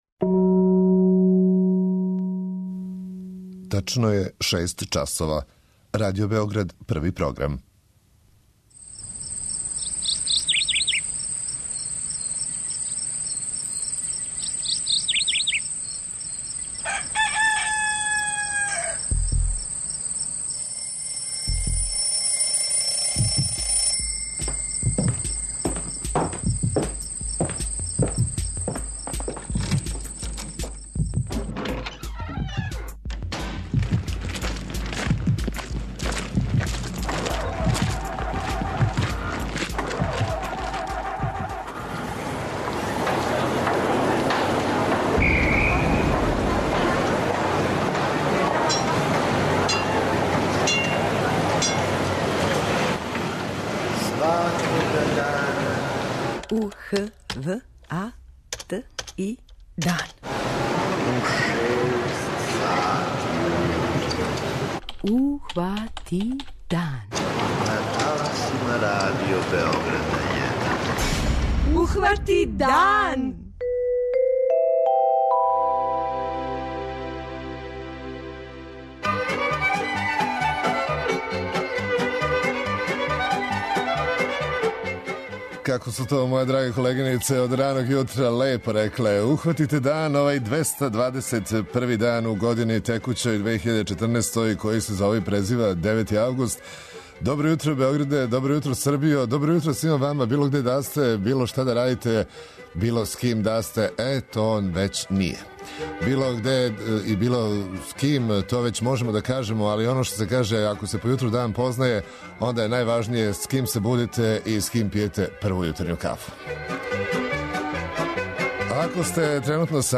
Од нашег дописника из Ужица, чућете како су временске неприлике утицале на туристичку сезону на Златибору.
преузми : 57.27 MB Ухвати дан Autor: Група аутора Јутарњи програм Радио Београда 1!